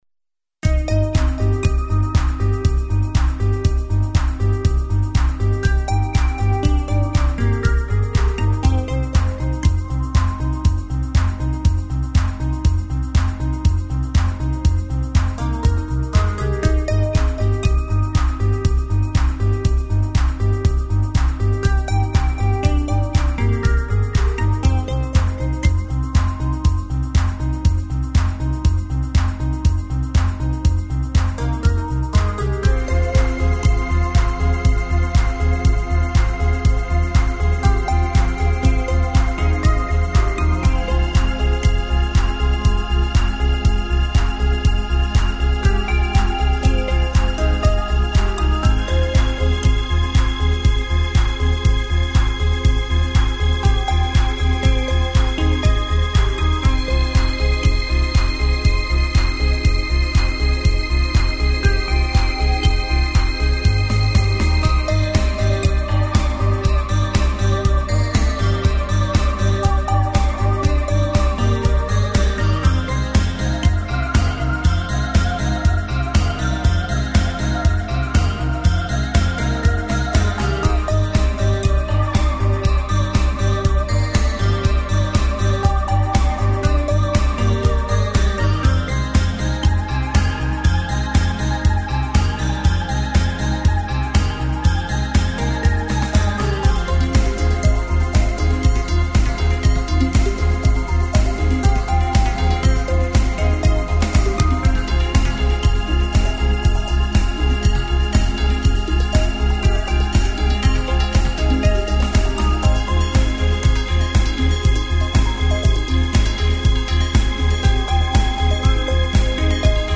• Жанр: Танцевальная
инструментальная композиция